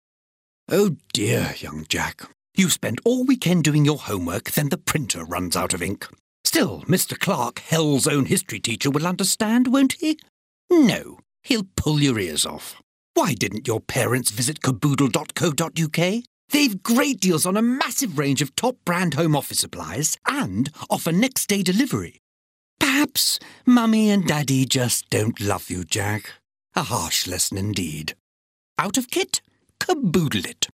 Voice Reel
James Dreyfus - Caboodle - Witty, Well Spoken
James Dreyfus - Caboodle - Witty, Well Spoken.mp3